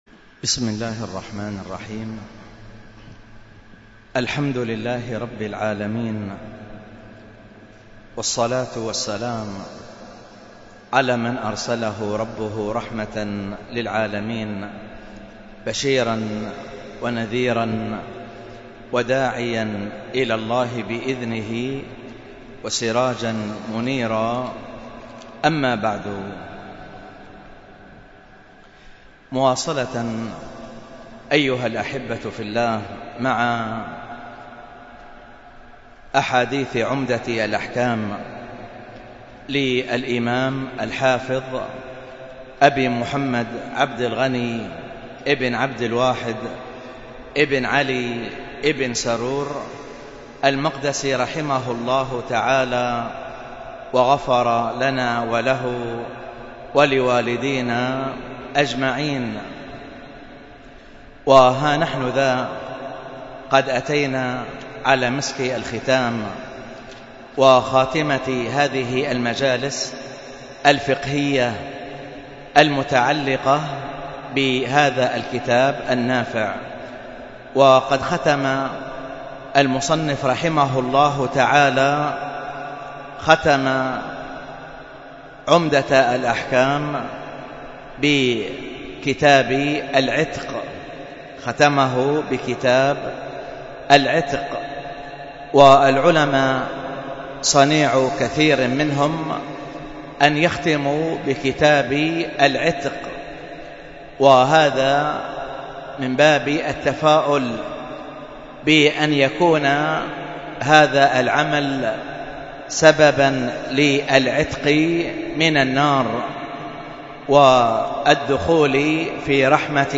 الدرس في كتاب البيوع 67، ألقاها